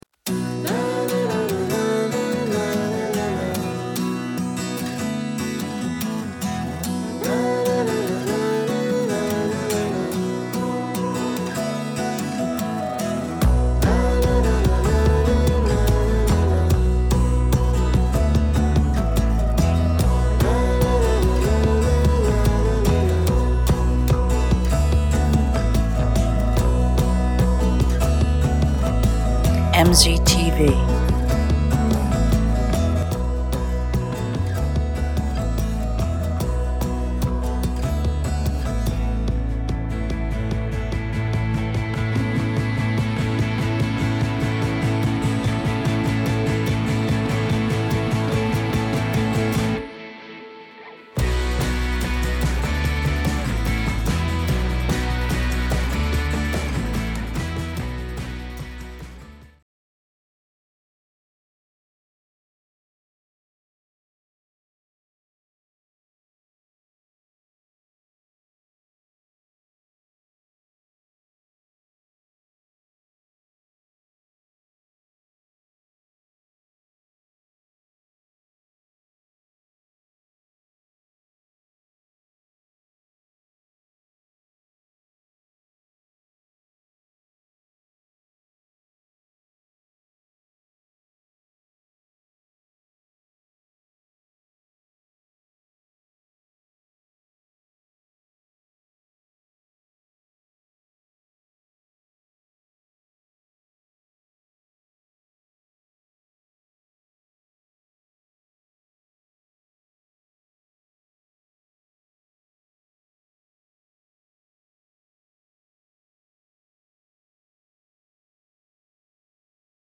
steps up to the microphone at the Columbia, SC conference back in September and encourages the saints to love one another.